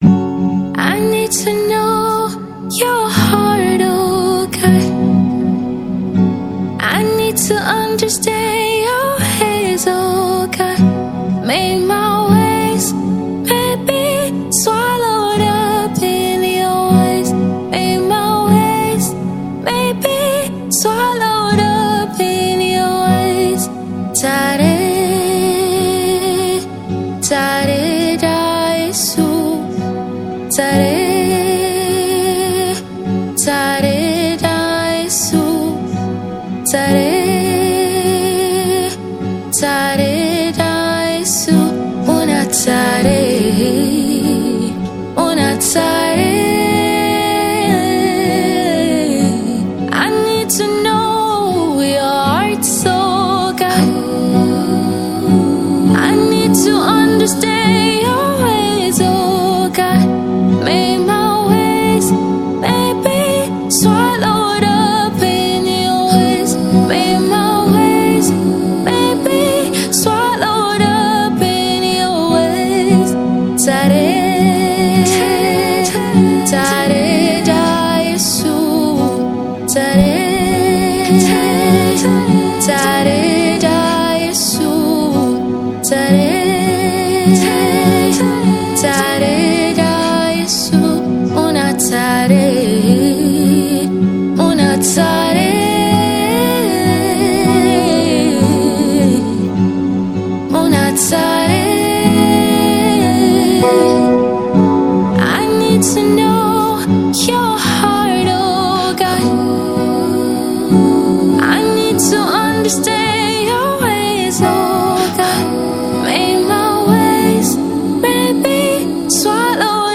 Hausa Gospel Music